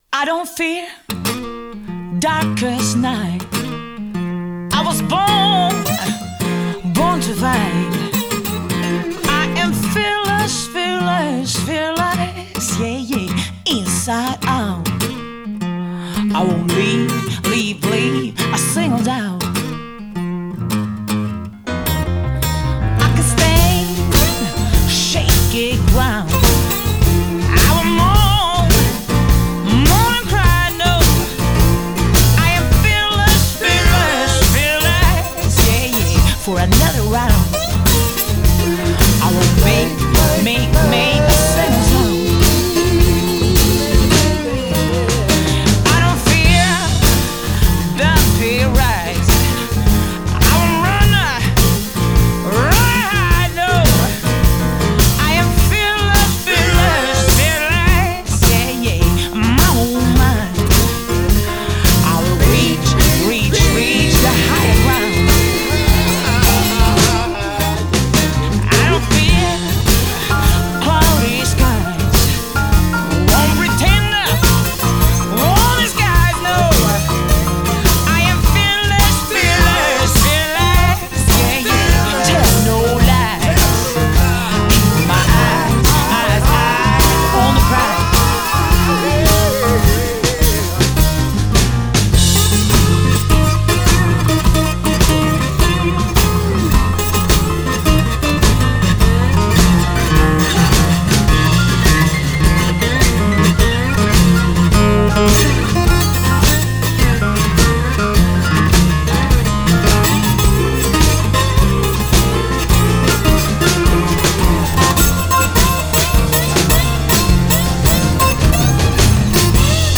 Summertime Festival 2011.